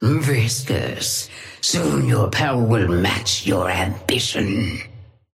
Sapphire Flame voice line - Viscous, soon your power will match your ambition.
Patron_female_ally_viscous_start_07.mp3